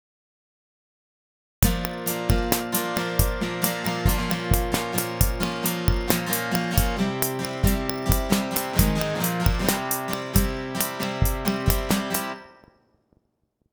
サビっぽい感じの部分
チープなリズムパターンの上にそのギターを重ねてみた。
B-melo-guitar-CK78-01.m4a